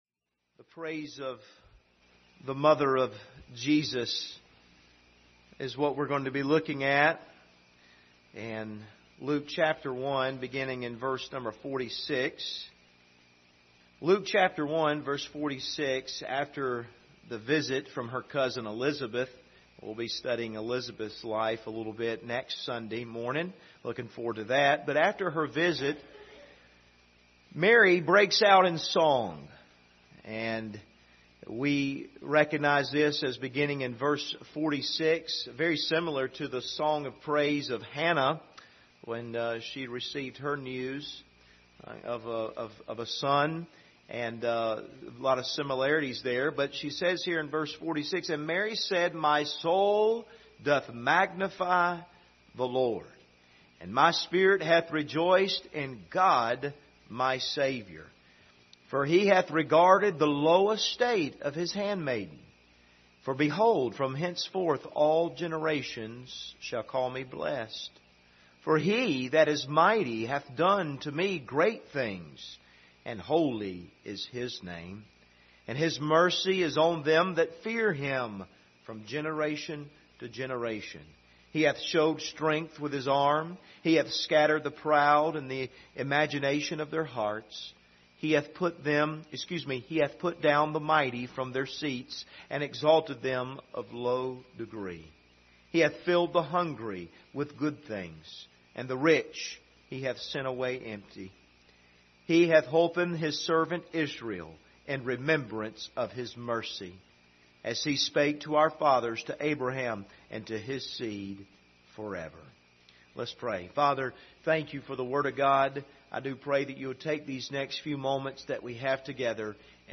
Passage: Luke 1:46-55 Service Type: Sunday Evening